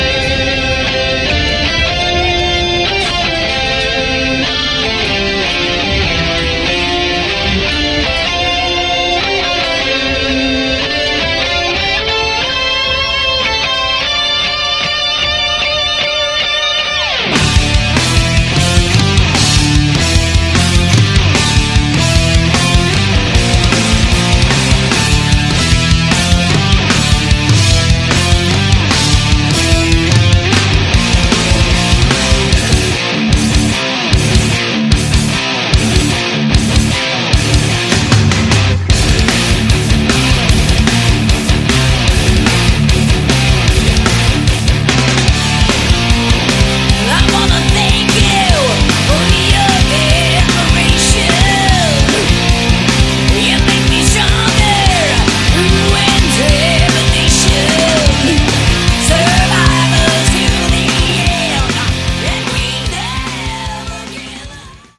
Category: Melodic Metal
lead and backing vocals
guitars, backing vocals
bass
drums, backing vocals